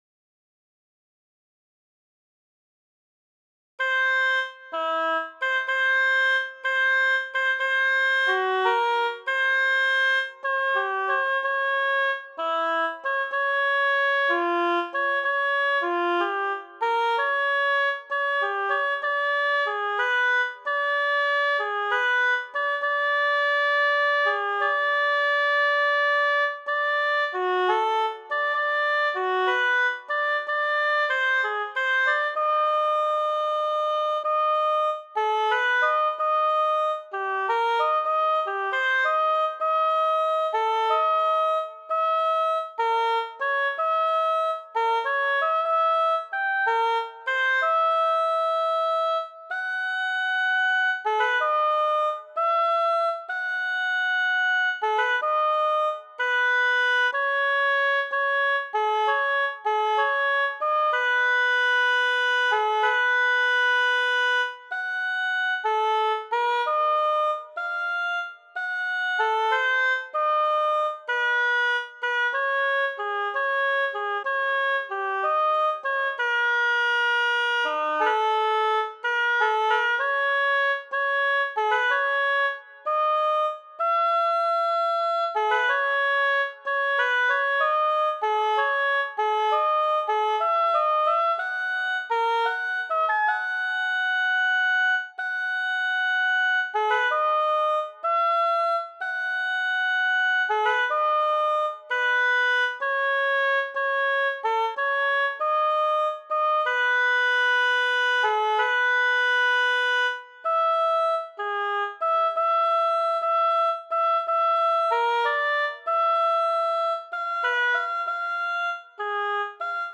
Die Rhythmik ist locker und spielerisch.
Komplettes Stück in langsamem Übungs-Tempo.